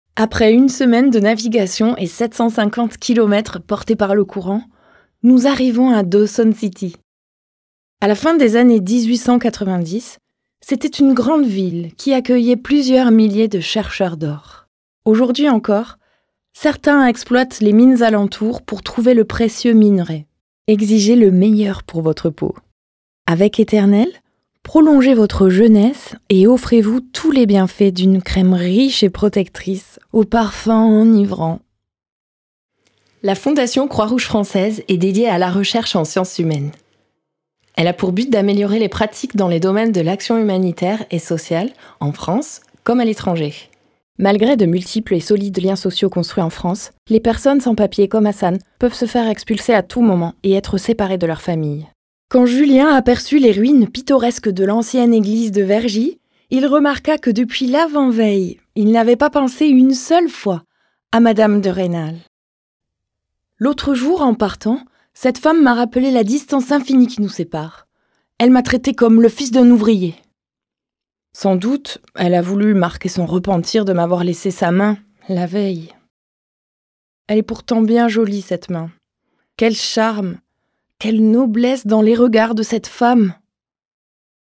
Voix
Comédienne
15 - 45 ans - Mezzo-soprano